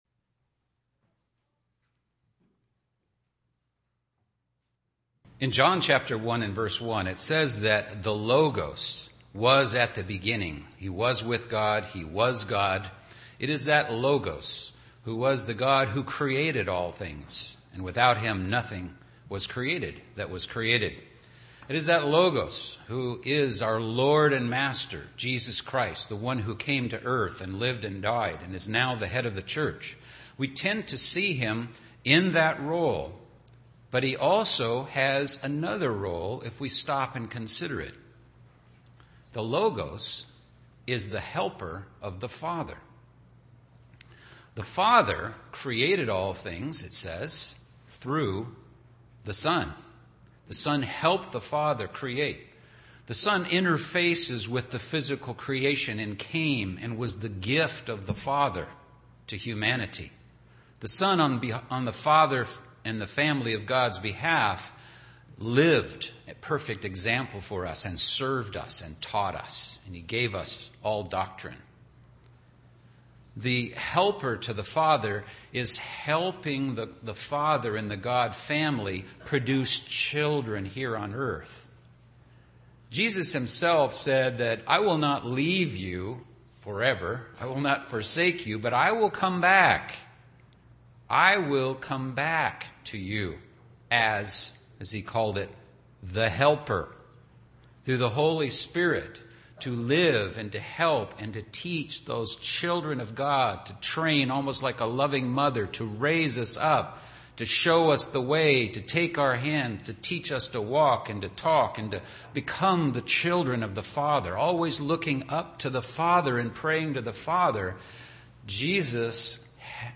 God made men and women with unique characteristics. This sermon examines directives from Scripture geared toward women intended to promote a fulfilled life filled with respect from God as well as other people.